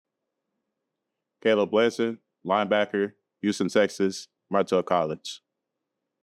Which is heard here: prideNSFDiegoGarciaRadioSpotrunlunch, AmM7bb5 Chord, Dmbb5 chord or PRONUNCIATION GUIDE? PRONUNCIATION GUIDE